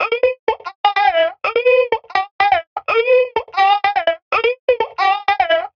Index of /90_sSampleCDs/Sample Magic - Transmission-X/Transmission-X/transx loops - 125bpm